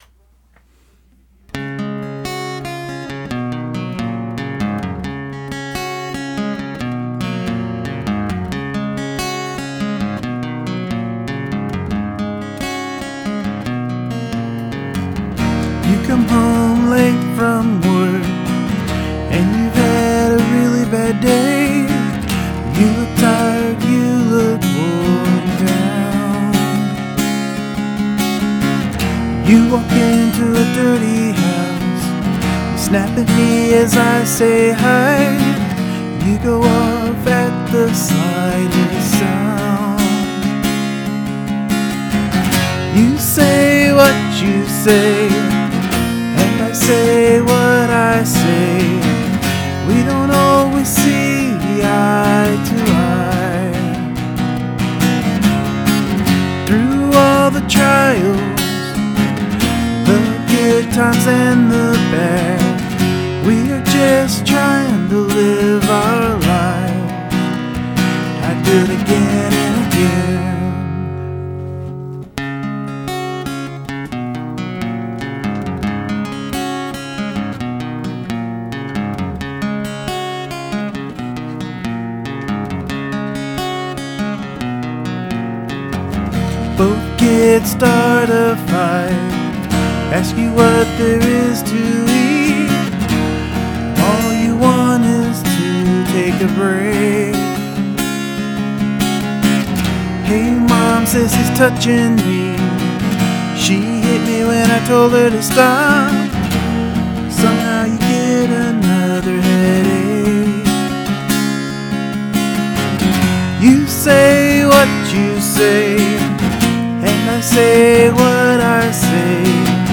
guitar: Yamaha Acoustic/Electric
Vox: AT2020
RecordeD: BR-900CD
Lovely fresh recording, good delivery, and lyrics we can all identify with.
I love your stripped down style.
Edgy-yet-pretty guitar, intensely emotional vocal.
Lots of emotion.